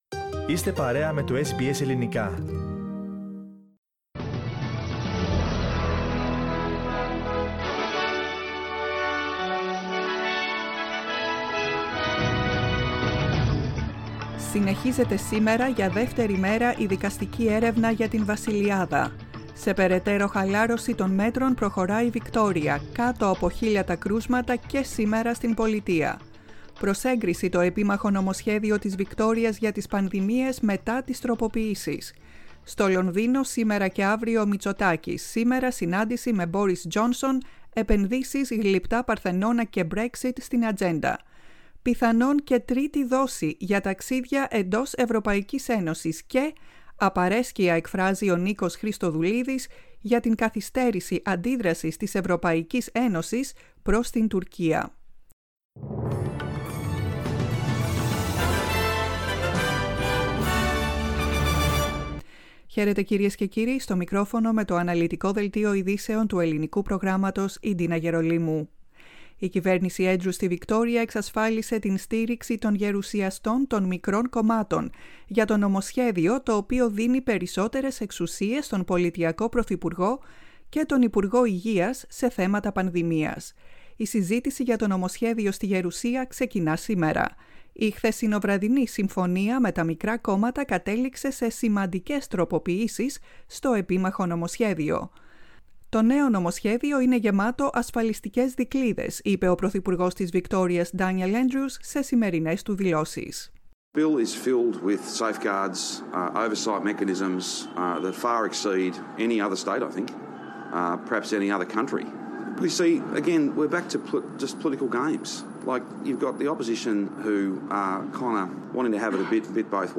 News in Greek, 16.11.21
The main bulletin of the day from the Greek Program.